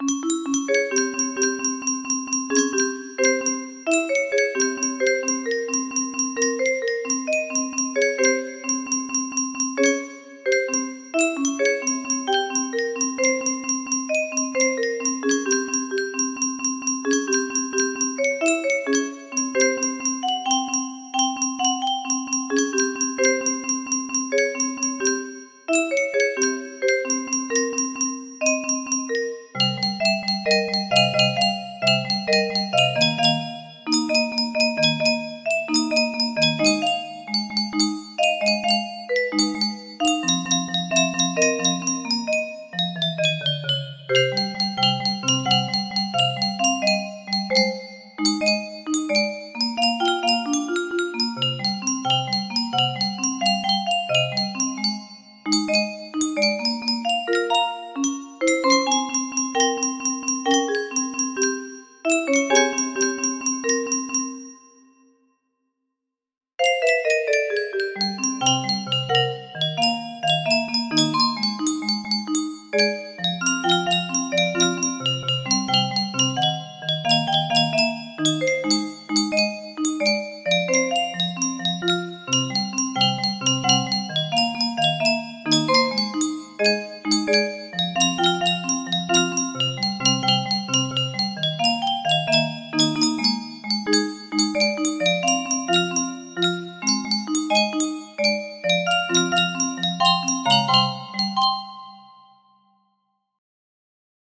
Full range 60
Imported from MIDI from imported midi file (23).mid